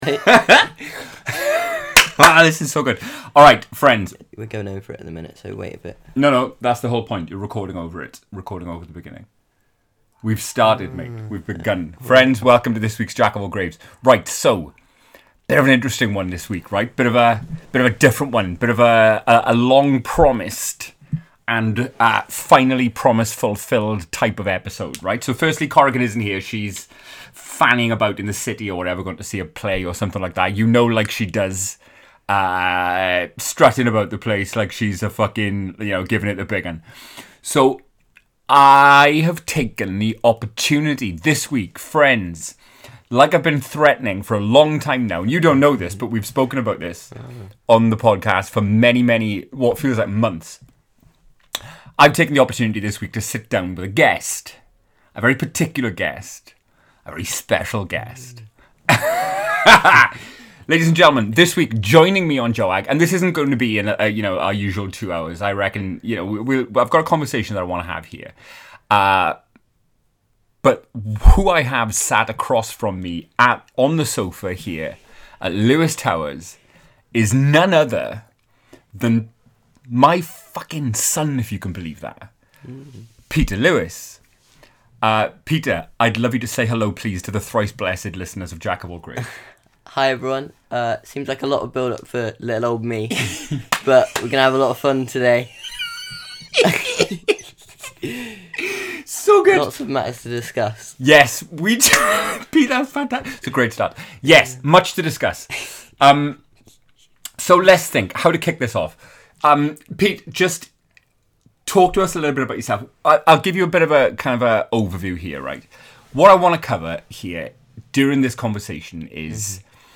A storytelling pod in which an American girl and a Welsh guy bond over a shared love of the terrifying, tragic, bloody, morbid, anxiety-inducing, and horrific -- a joyful and therapeutic exploration of very dark things.